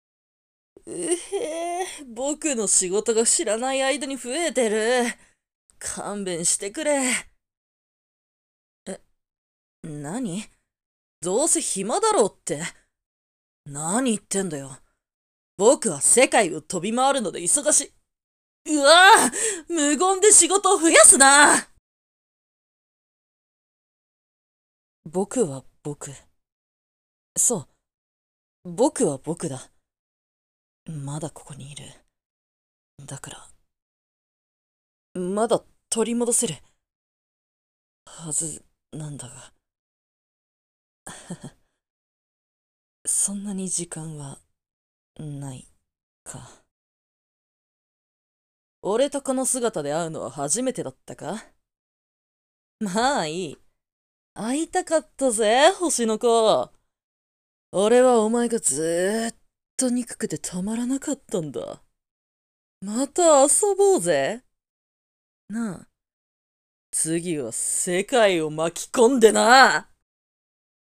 影の君 台詞